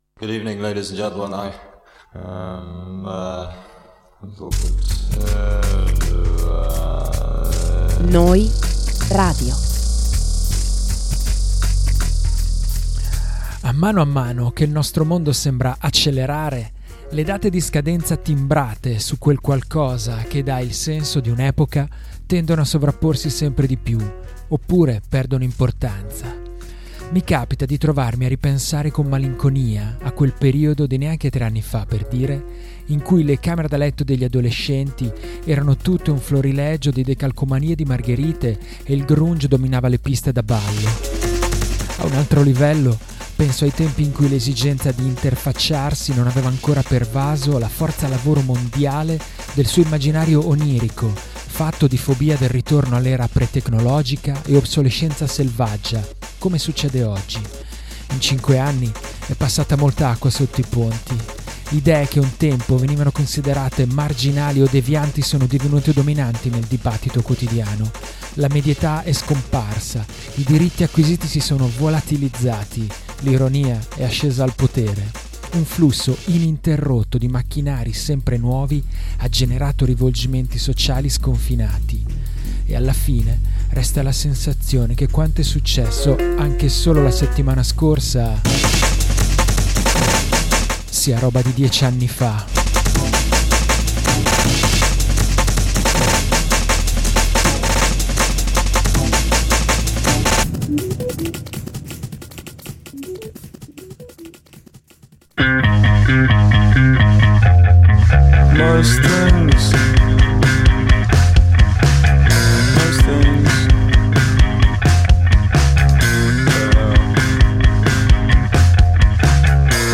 Podcast di novità indiepop, indie rock, shoegaze, post-punk, lo-fi e twee!